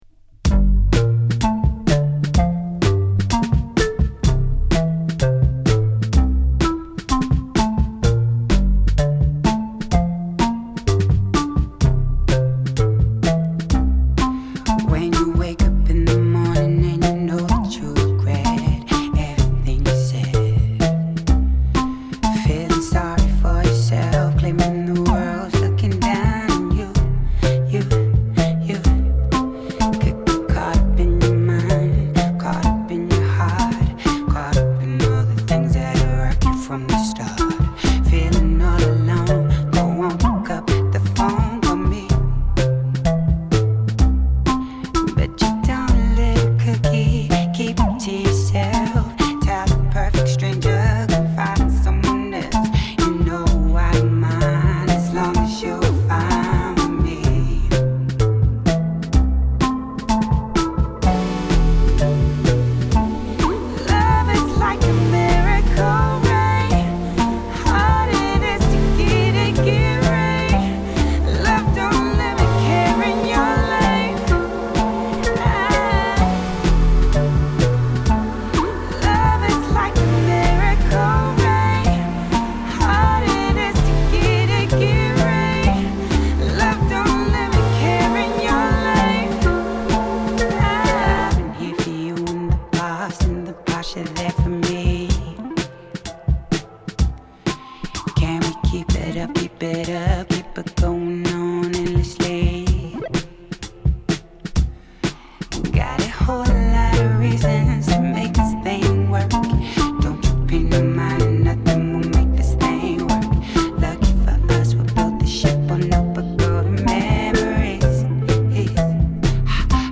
ニュージーランドのアーバン・ミュージック・シーンを代表する女性シンガー
ソウルフルなエレクトロ・ミュージック